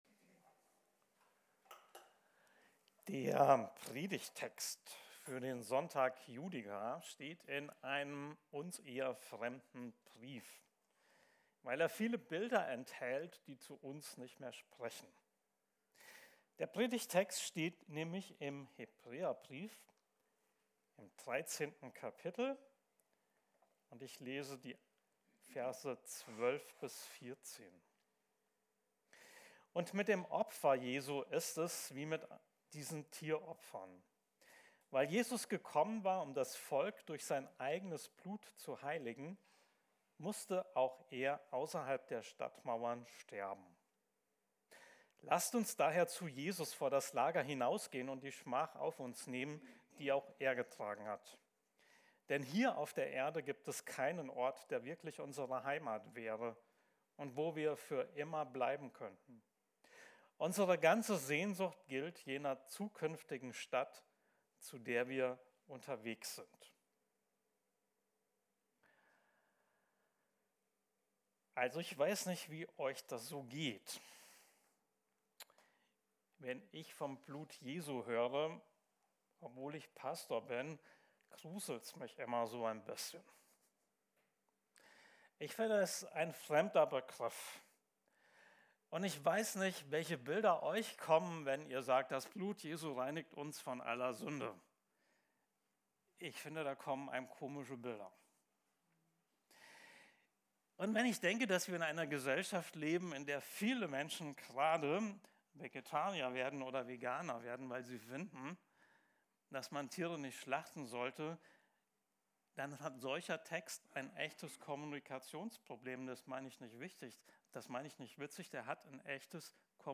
Gottesdienst am 30. März 2026